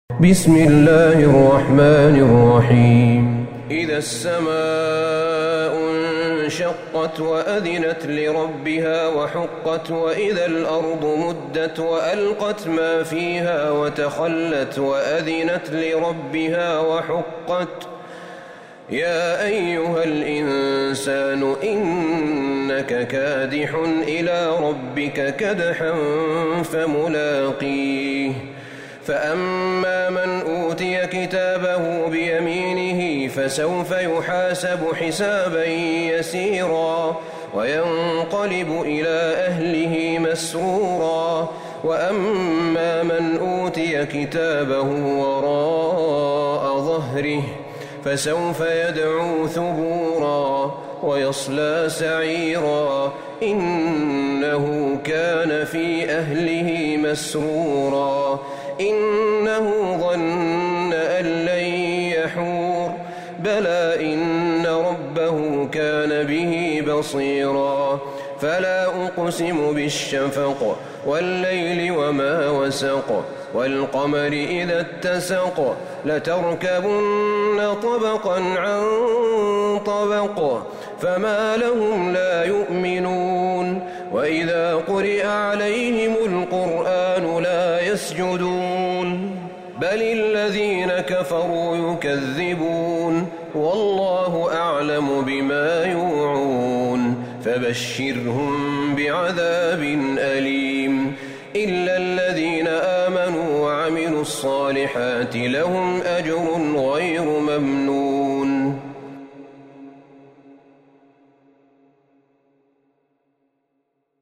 سورة الانشقاق Surat Al-Inshiqaq > مصحف الشيخ أحمد بن طالب بن حميد من الحرم النبوي > المصحف - تلاوات الحرمين